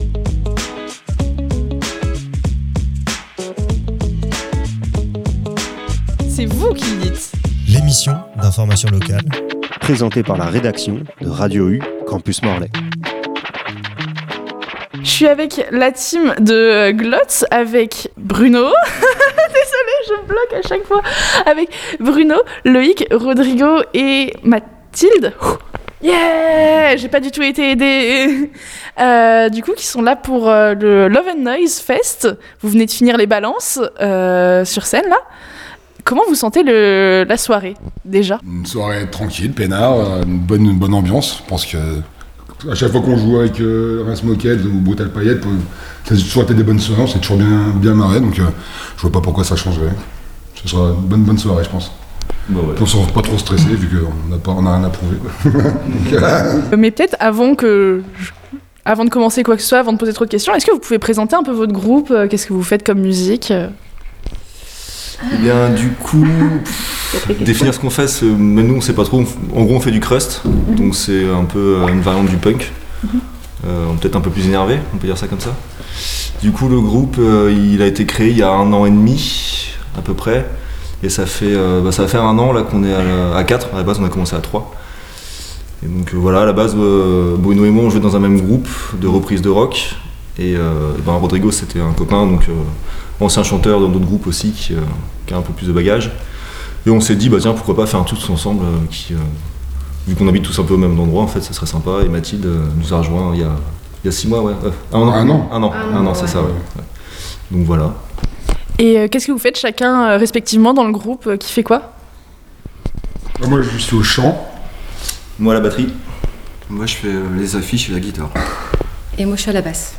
Le 14 février à Cléder, nous avons eu l’occasion d’interviewer le groupe Glotte qui se présentait au festival Love and Noise. Nous avons pu avoir un échange immersif qui permet de découvrir leurs influences, les coulisses du groupe et l’énergie qui les anime.